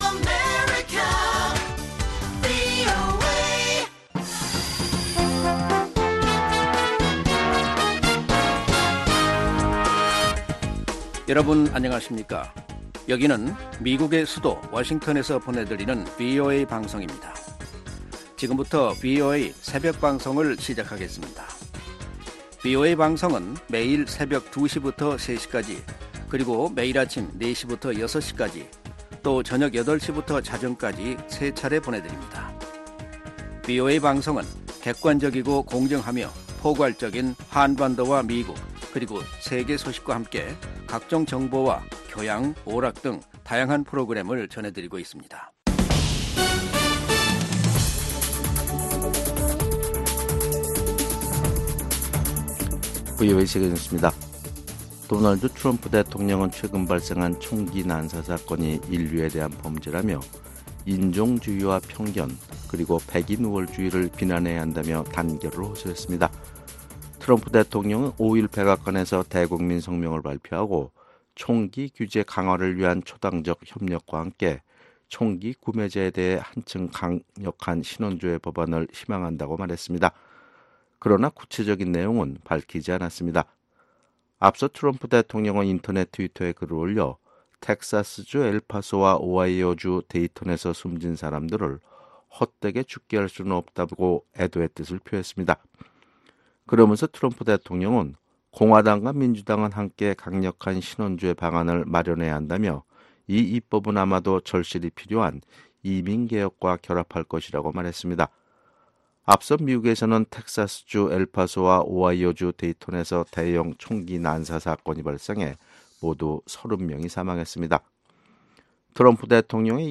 VOA 한국어 '출발 뉴스 쇼', 2019년 8월 6일 방송입니다. . 미군과 한국군의 연합군사훈련이 5일 시작됐습니다. 전문가들은 북한의 강도 높은 반발을 예상하고 있지만 아직까지 북한의 별다른 움직임은 나타나지 않았습니다.